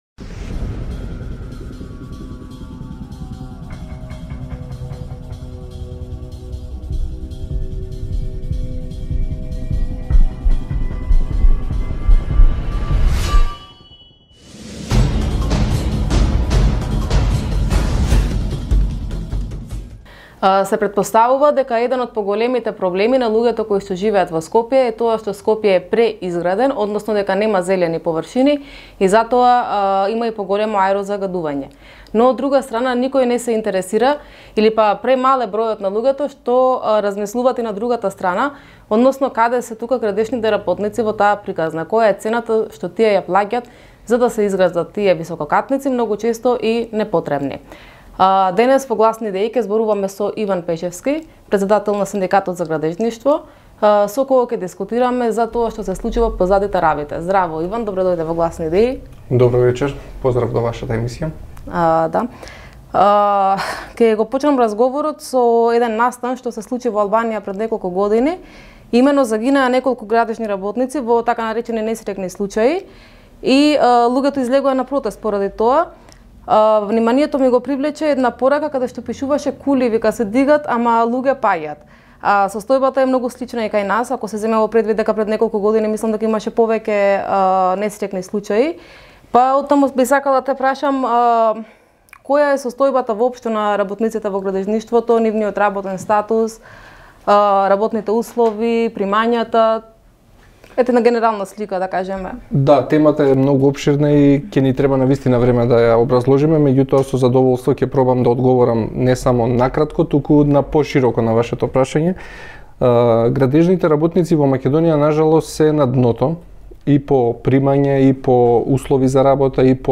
ИНТЕРВЈУ: Кули се дигаат, луѓе паѓаат / INTERVISTË: Kulla ngrihen, njerëz bien